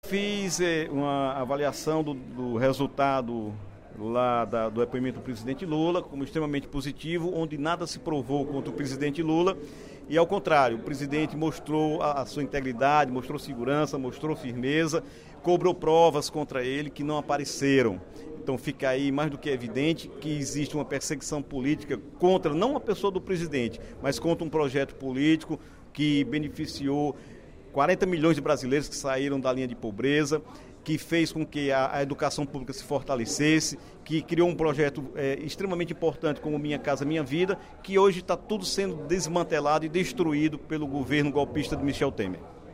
O deputado Dr. Santana (PT) anunciou, no primeiro expediente da sessão plenária desta quinta-feira (11/05), que a Comissão de Seguridade Social e Saúde da Assembleia Legislativa realizará audiência pública, no sábado (13/05), a partir das 9h, no Cineteatro Neroly Filgueira, em Barbalha, para debater a crise financeira dos hospitais privados da região do Cariri.